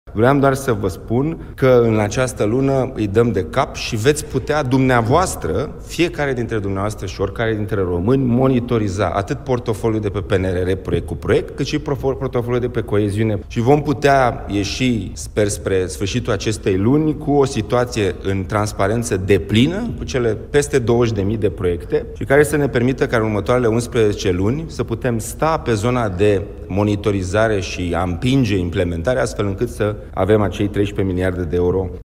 Ministrul Proiectelor Europene, Dragoș Pîslaru: „Oricare dintre români va putea monitoriza atât portofoliul de pe PNRR, proiect cu proiect, cât și portofoliul de pe coeziune”